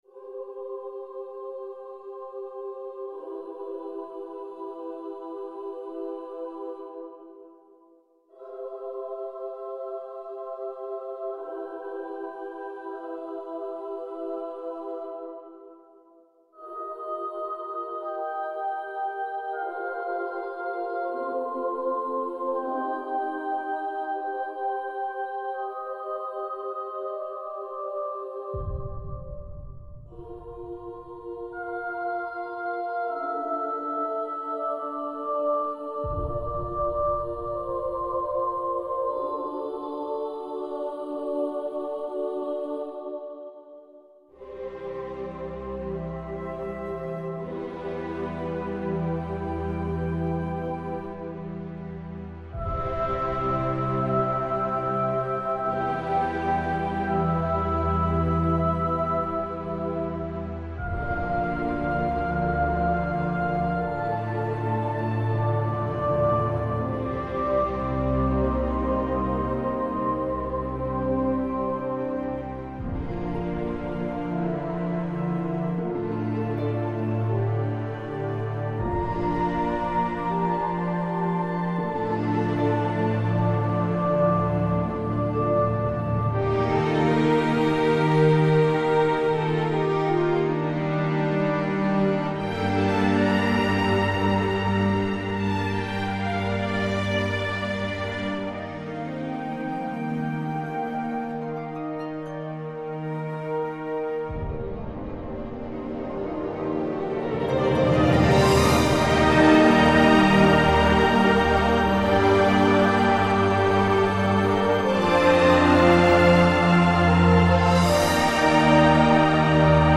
High impact dramatic film score music.
Tagged as: Electronica, Orchestral, Instrumental